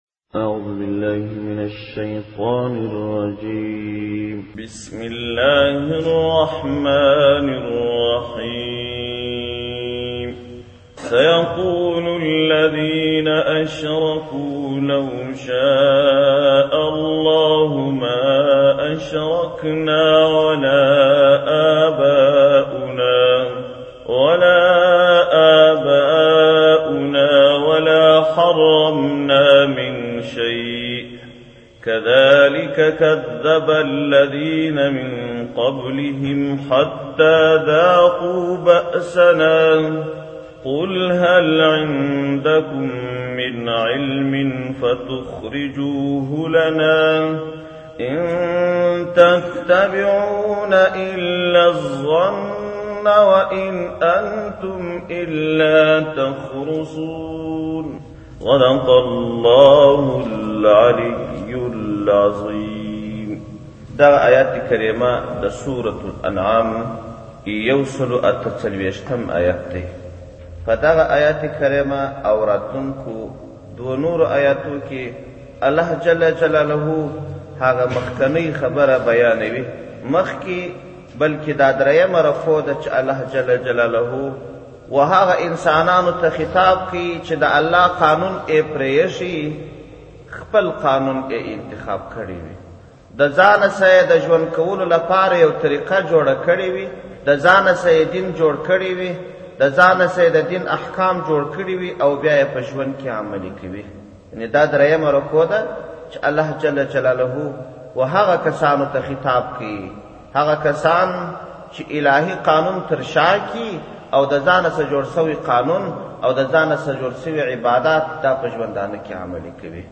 جنوري 9, 2017 تفسیرشریف, ږغیز تفسیر شریف 941 لیدنی